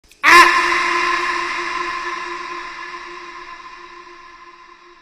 Efek suara ACK
Kategori: Suara meme
ack-sound-effect-id-www_tiengdong_com.mp3